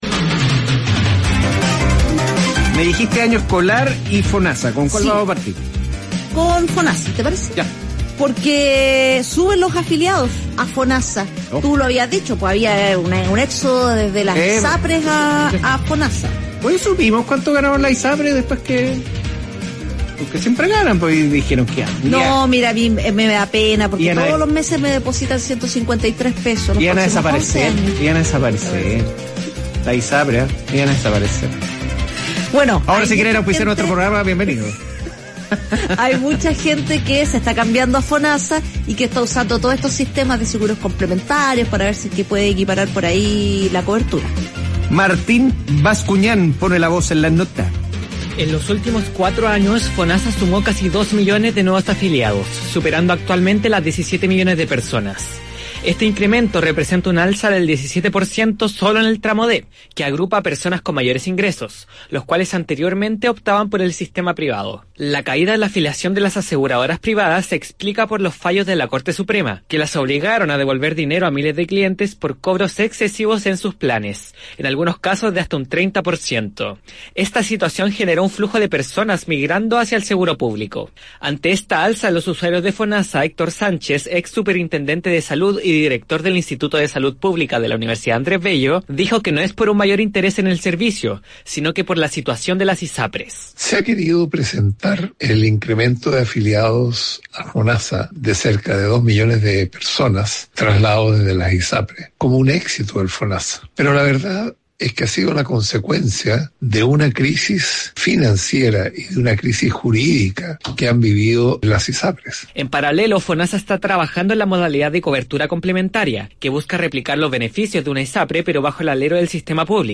En una nota del 5 de marzo transmitida por Radio ADN